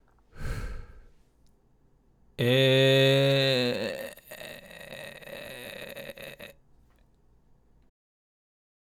まずは、肺から空気を抜いた状態でグーの声「え」を出し、そこから徐々に喉を締めていきながら潰れた音を出していきます。
※見本音声